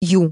OCEFIAudio_ru_LetterU.wav